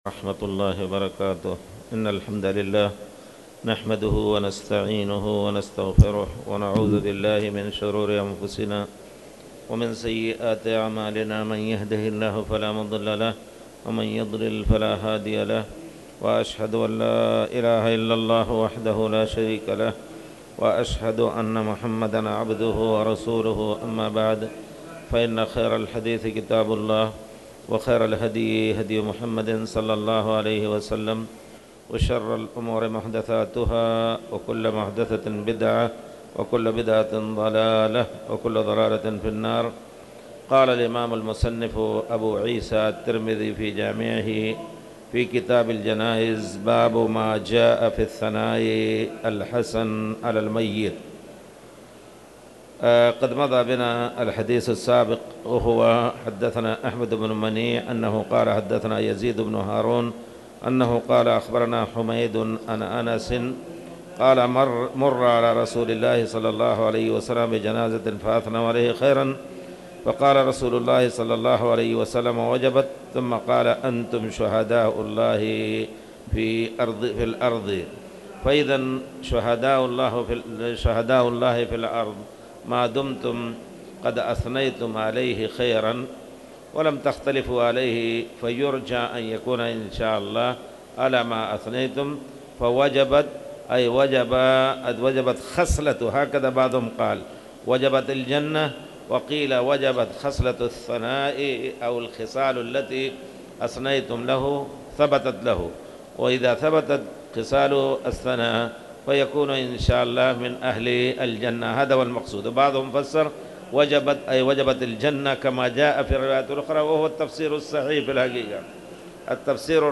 تاريخ النشر ١٥ شوال ١٤٣٧ هـ المكان: المسجد الحرام الشيخ